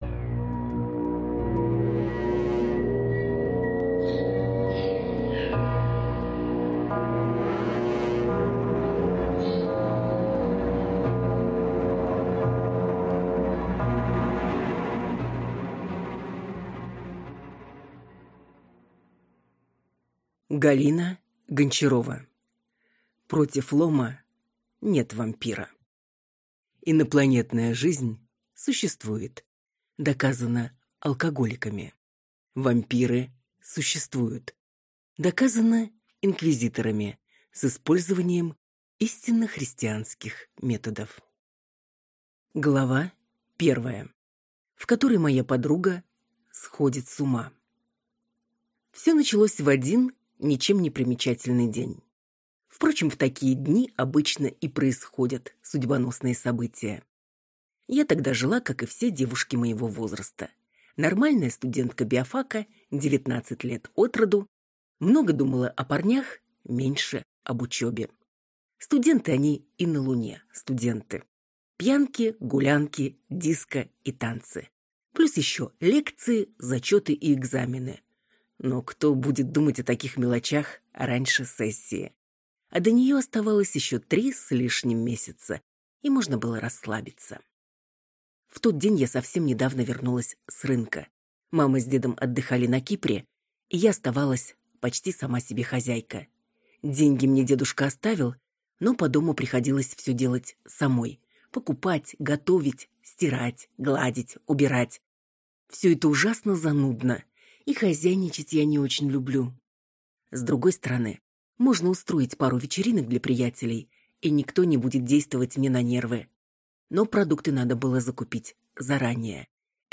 Аудиокнига Против лома нет вампира | Библиотека аудиокниг
Прослушать и бесплатно скачать фрагмент аудиокниги